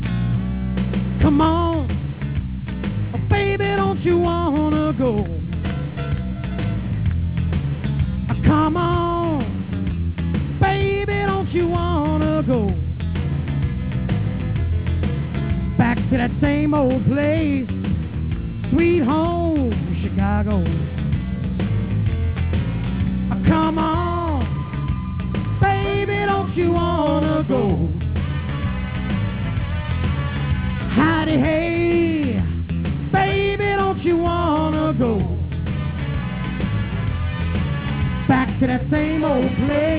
LIVE in their hometown